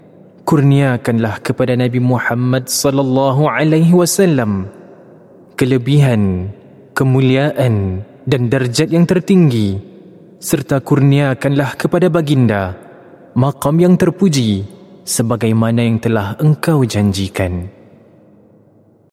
Voice Samples: Call to Prayer
male